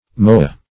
moa \mo"a\ (m[=o]"[.a]), n. [Native name.] (Zool.)